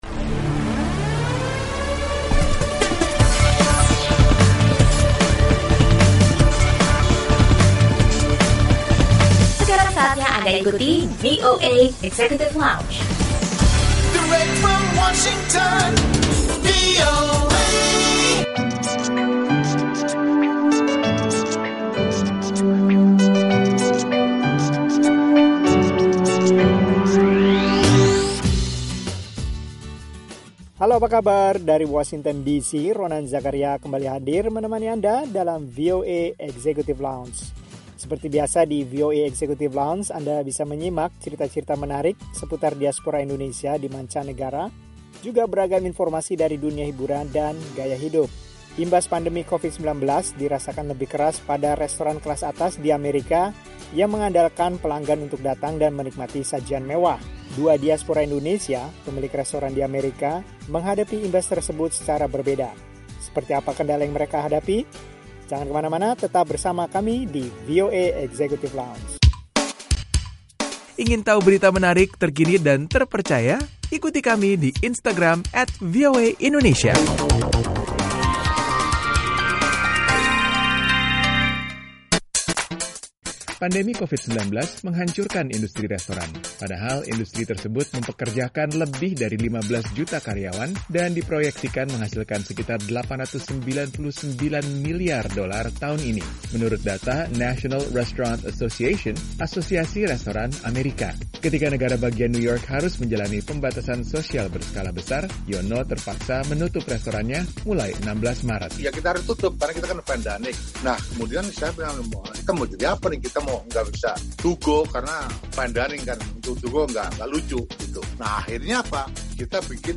Obrolan bersama Diaspora Indonesia pemilik restoran di Amerika mengenai usaha mereka di masa pandemi.